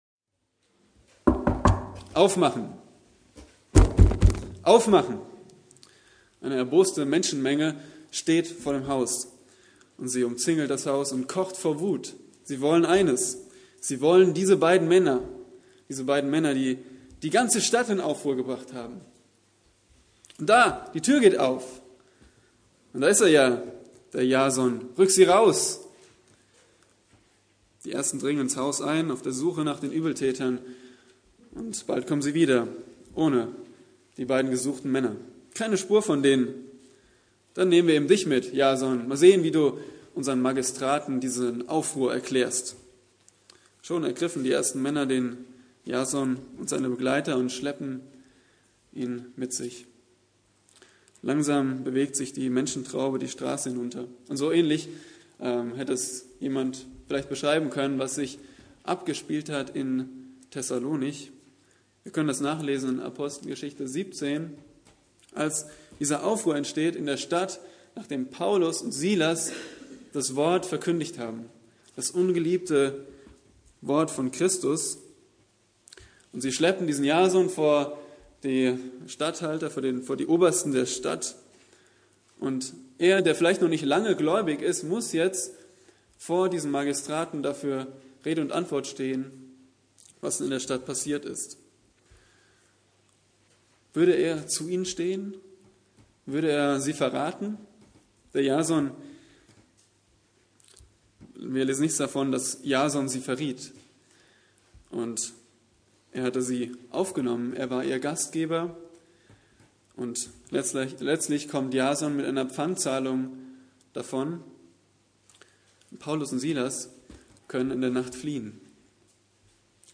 Eine predigt aus der serie "Der erste Petrusbrief."